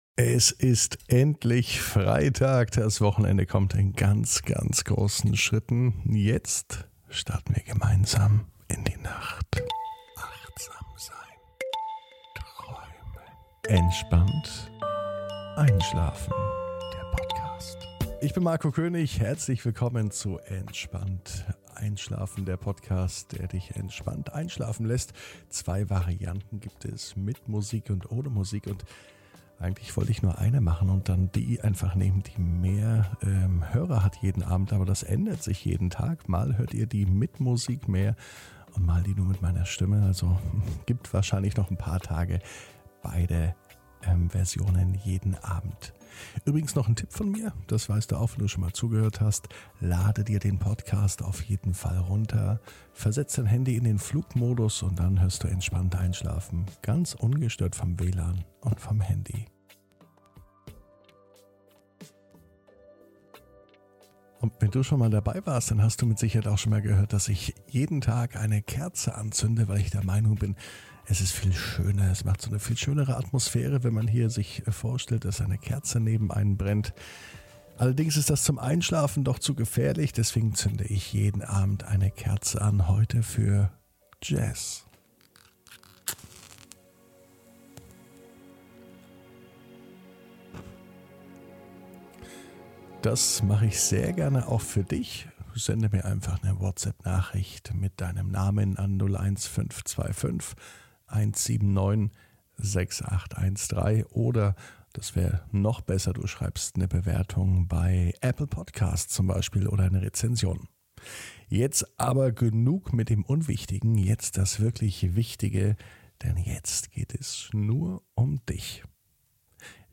(ohne Musik) Entspannt einschlafen am Freitag, 25.06.21 ~ Entspannt einschlafen - Meditation & Achtsamkeit für die Nacht Podcast